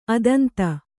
♪ adanta